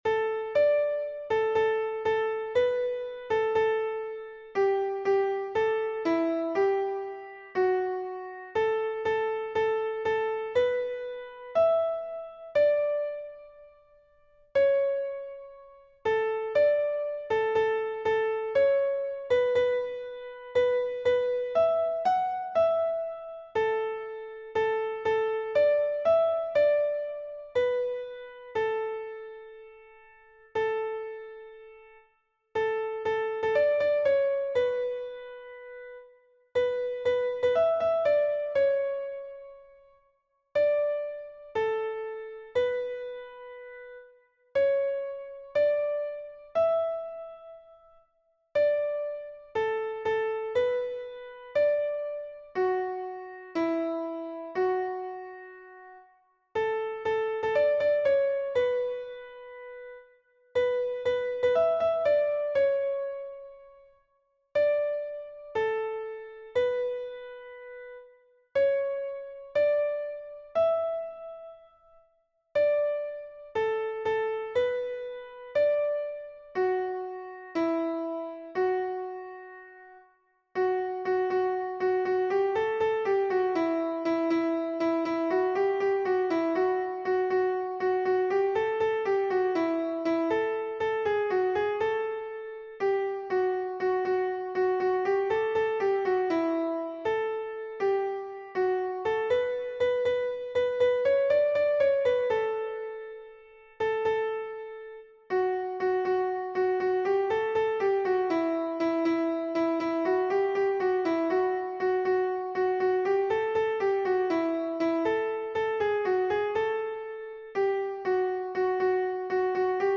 How Excellent Is Thy Loving Kindness — Soprano Audio.
How_Excellent_Is_Thy_Loving_Kindness_soprano.mp3